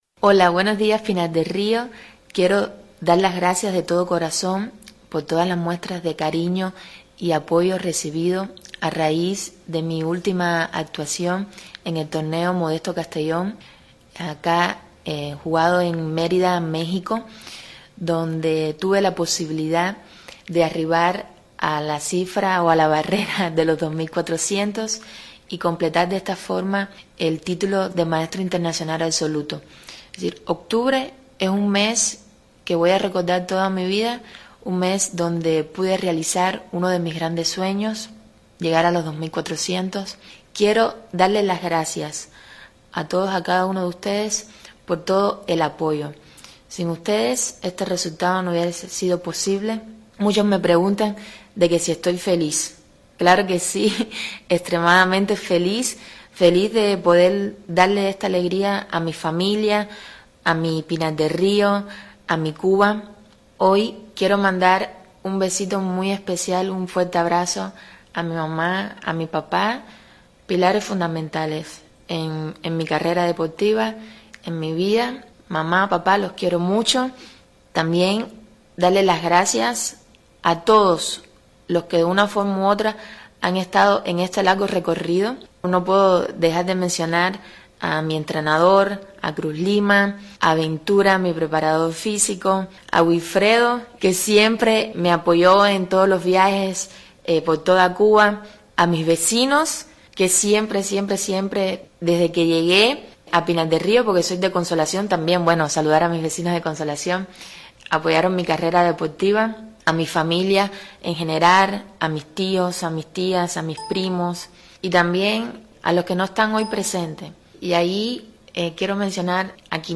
entrevistada por el periodista